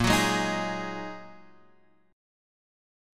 Bb7sus4#5 chord